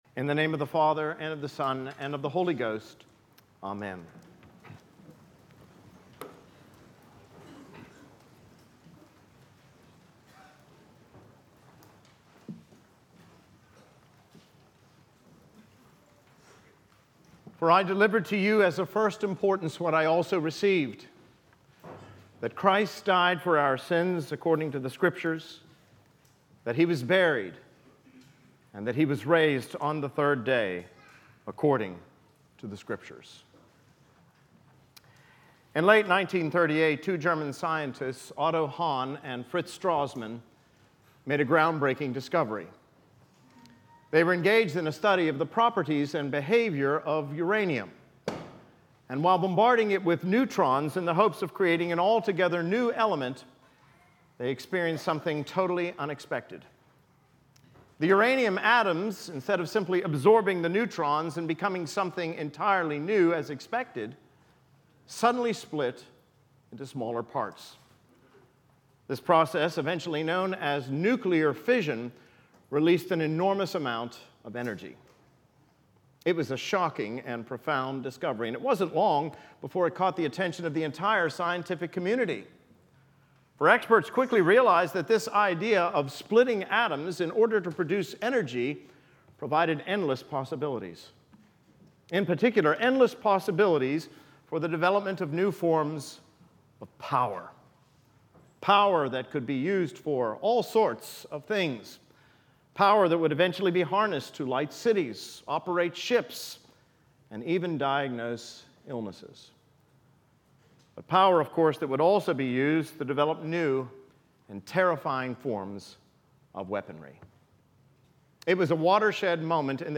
The Resurrection: / Easter Day: Festal Eucharist of the Resurrection